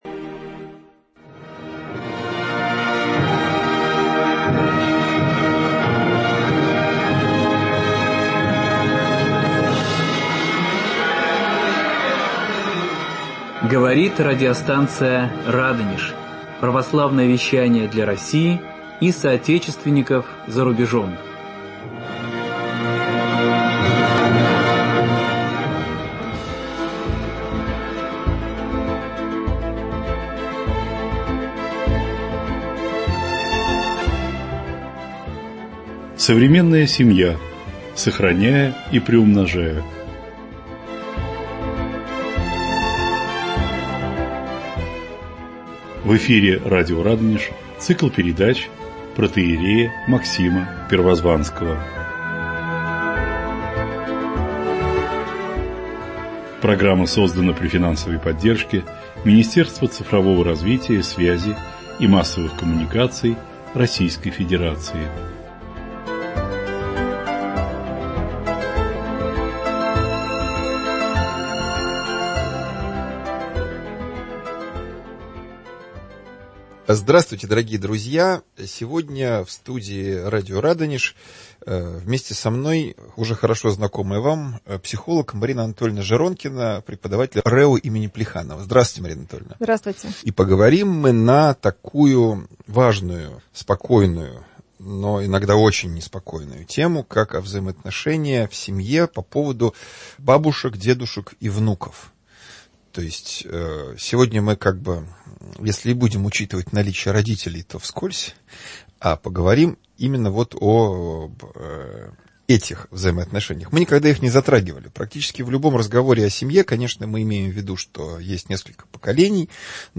Эфир от 21.06.2023 23:00 | Радонеж.Ру